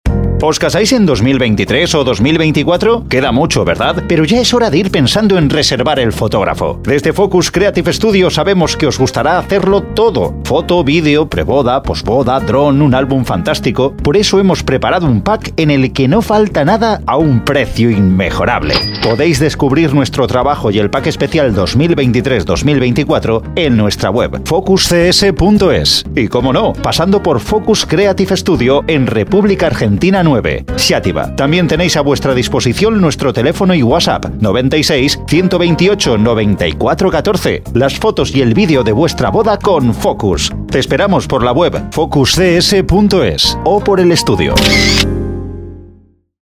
Spanish speaker, radio station voice, voice over, middle age voice.
Sprechprobe: Werbung (Muttersprache):